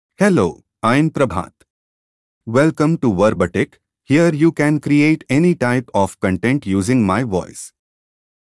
Prabhat — Male English (India) AI Voice | TTS, Voice Cloning & Video | Verbatik AI
Prabhat is a male AI voice for English (India).
Voice sample
Listen to Prabhat's male English voice.
Prabhat delivers clear pronunciation with authentic India English intonation, making your content sound professionally produced.